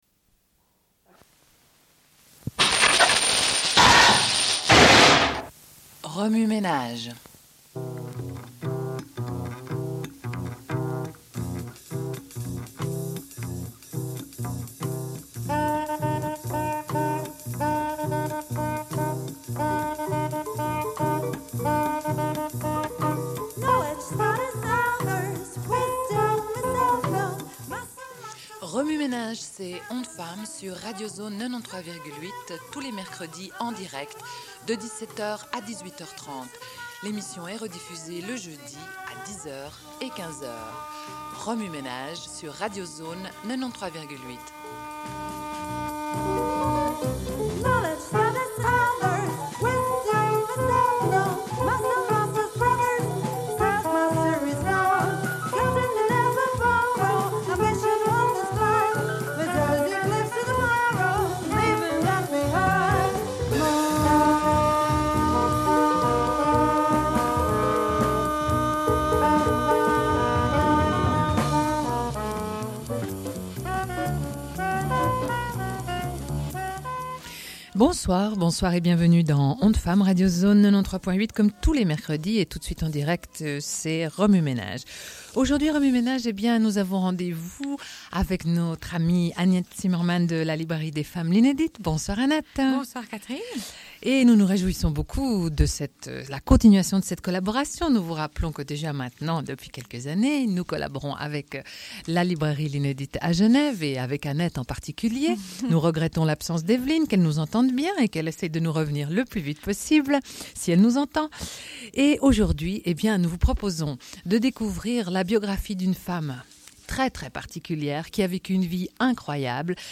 Une cassette audio, face A31:32